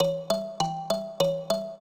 mbira